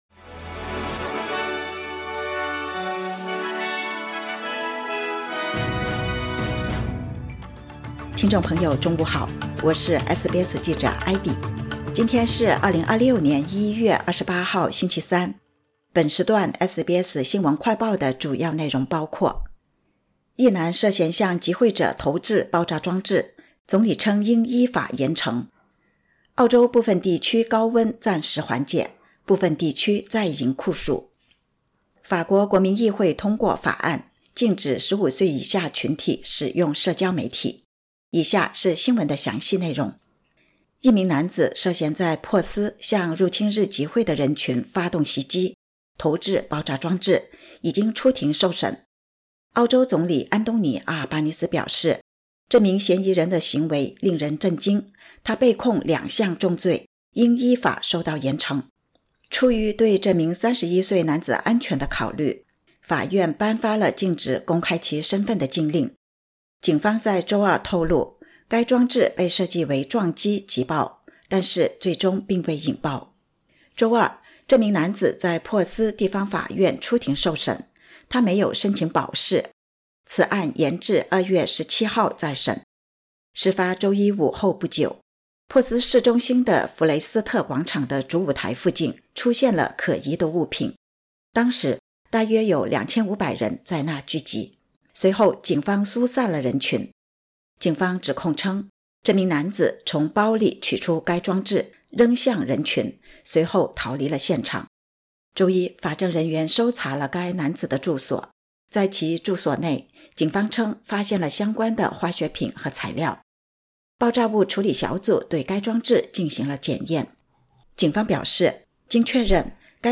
【SBS新闻快报】一男涉嫌向集会者投掷爆炸物 澳总理：应依法严惩